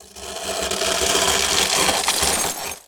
ice_spell_freeze_ground_02.wav